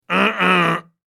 Nuh Uh Sound Effect
A male voice says “Nuh Uh” in a loud, funny way. It clearly expresses refusal or “no way.”
Nuh-uh-sound-effect.mp3